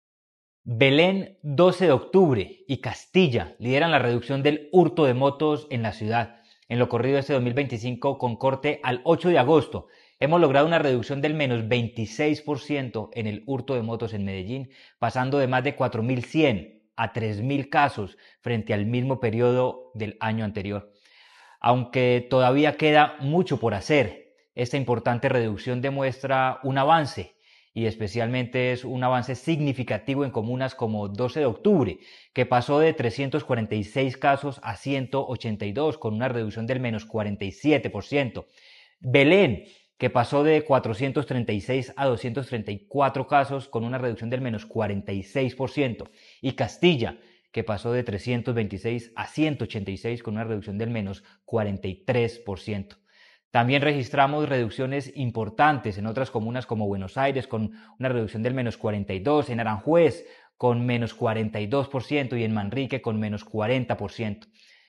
Declaraciones-del-secretario-de-Seguridad-y-Convivencia-Manuel-Villa-Mejia-1.mp3